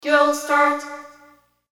/ F｜演出・アニメ・心理 / F-70 ｜other ロボットボイス